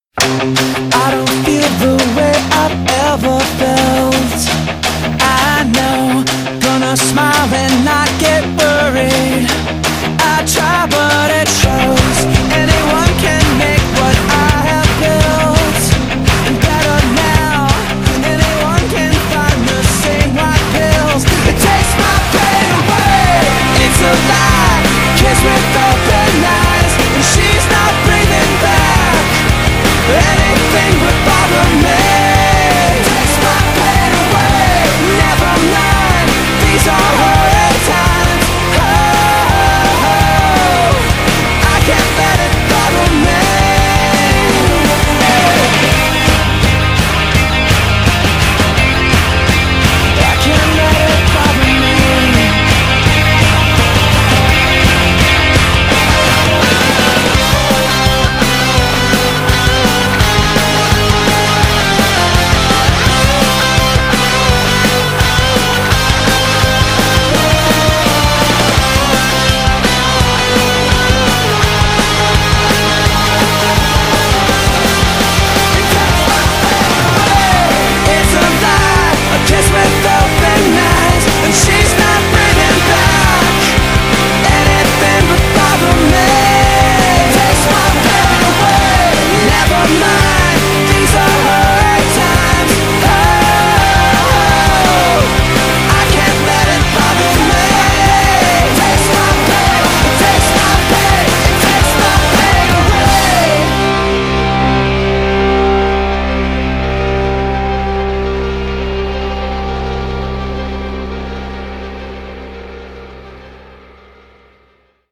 BPM168
Audio QualityMusic Cut